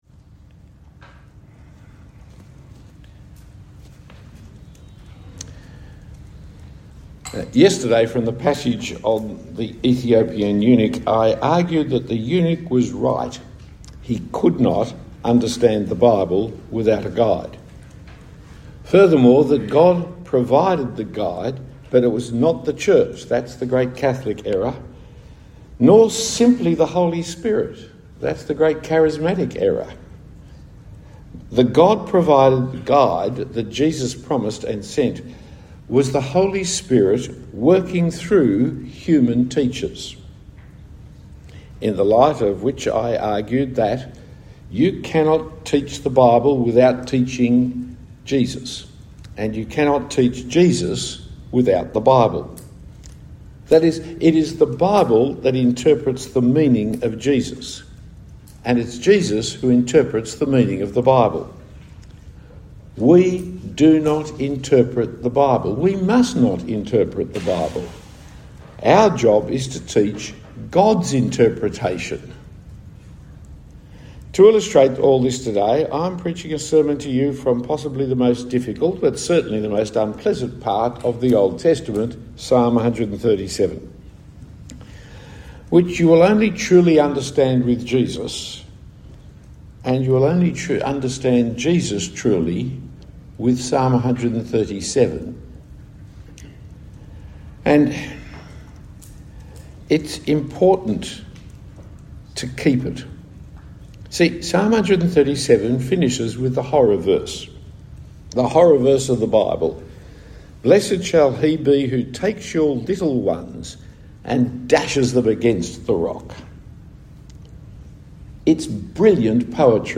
Talk 2 of 2 given at the FIEC Pastors’ Conference.